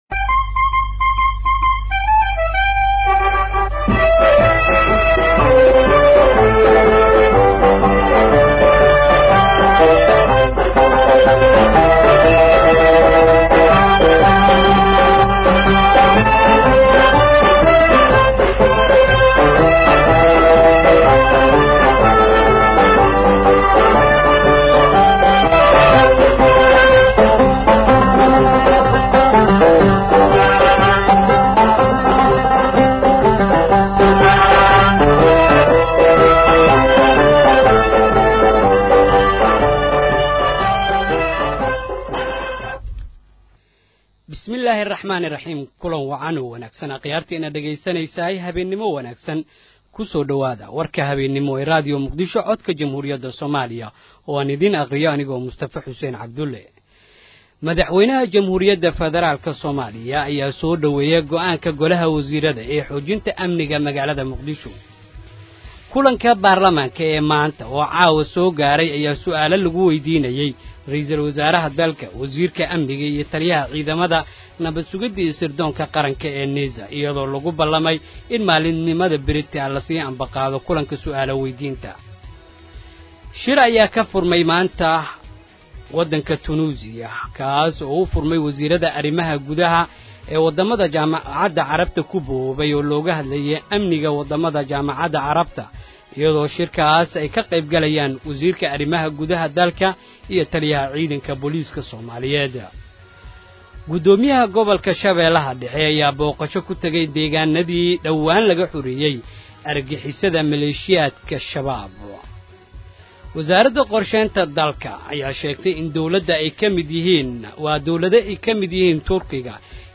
Dhageyso Warka Habeennimo Ee Radio Muqdisho